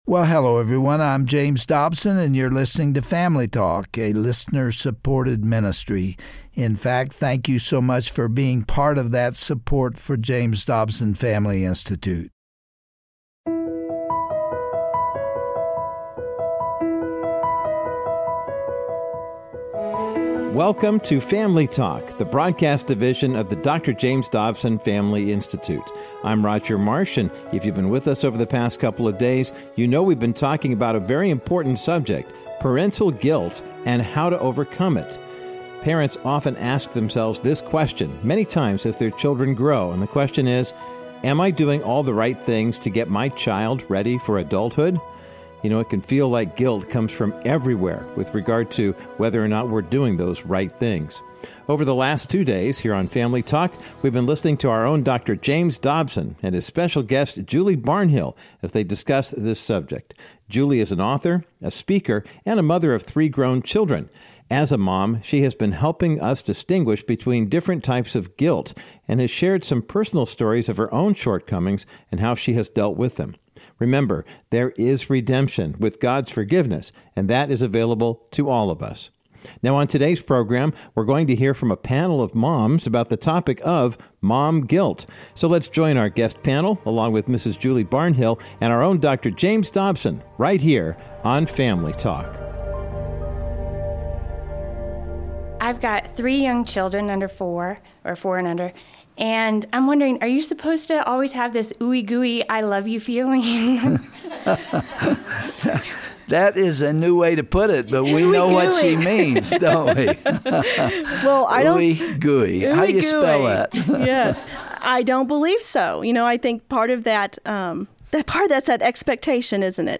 as they answer questions from a panel of moms on how to ride the emotional roller coaster of parenting. They also encourage moms and dads to avoid blaming themselves for the bad choices their children make.